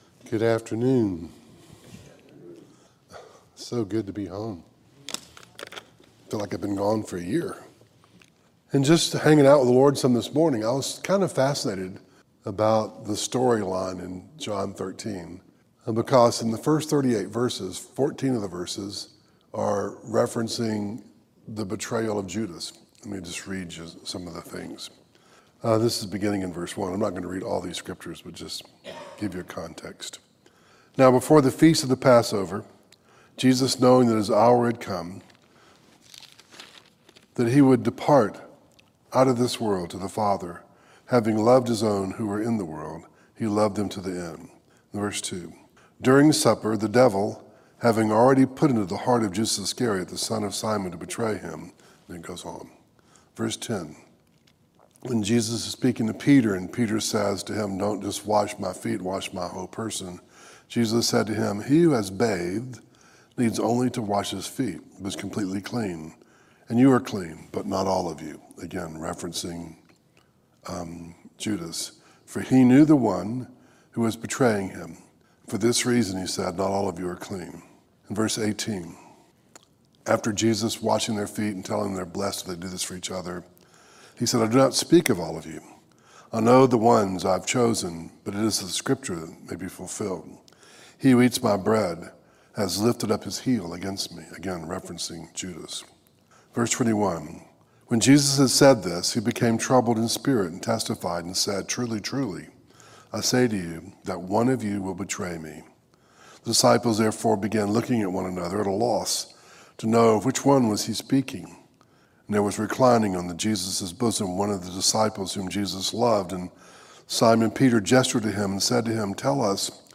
Series: Audio Devotionals
Service Type: Facebook Live